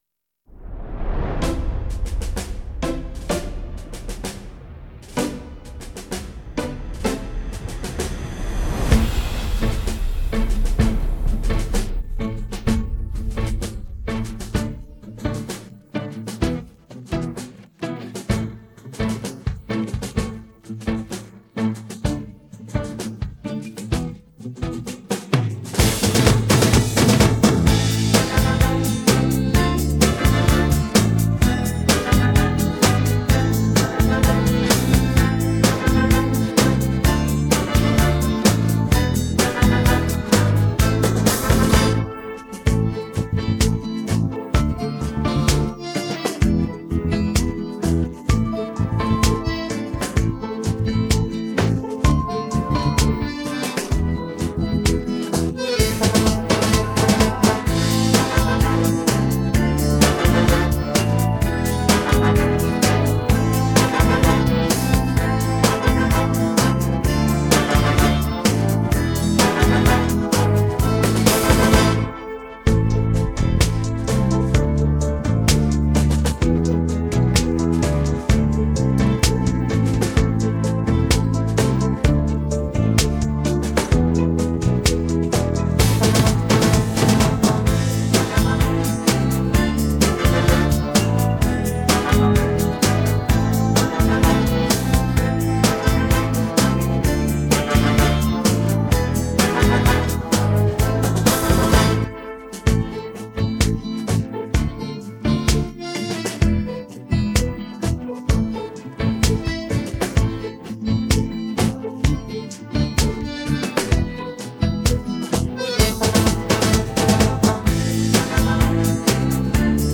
Слушать минус
караоке